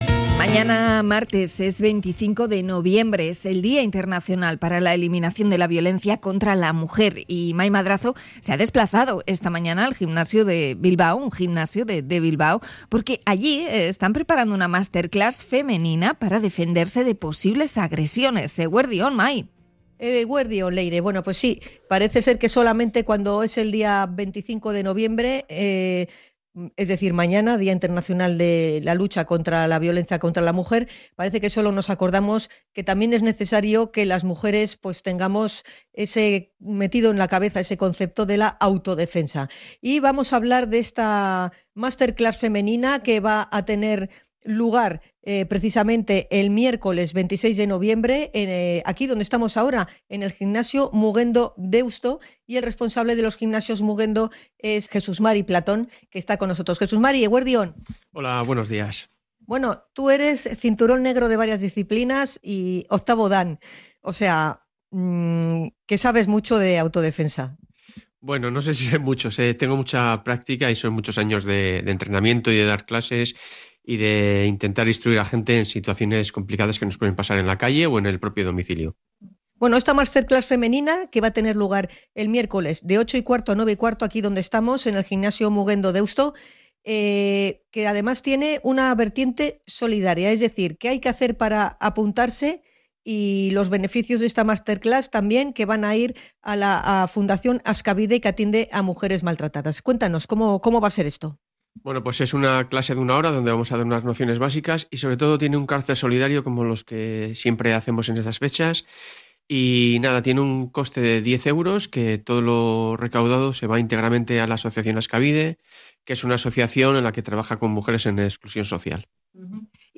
Podcast Sociedad